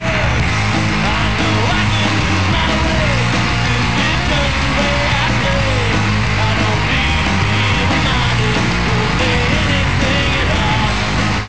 The end part is nice, it's more energetic